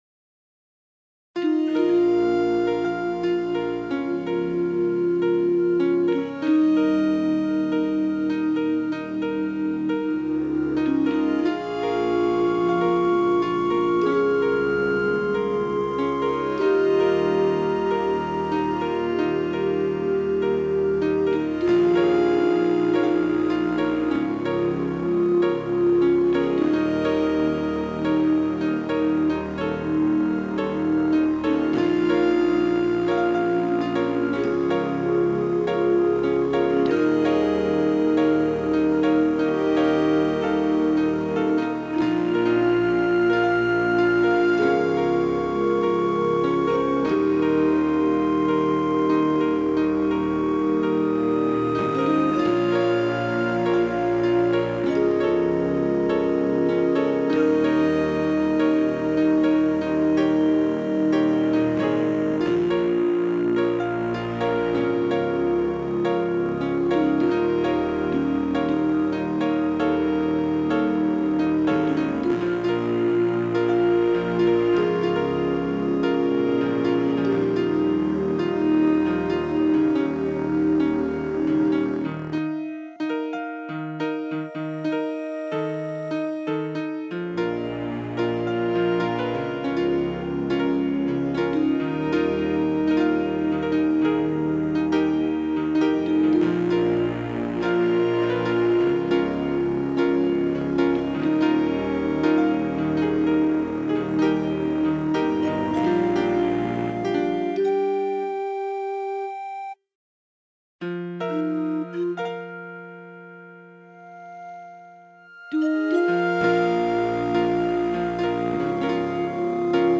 Strings, piano, Pan Flute,Sawtooth melody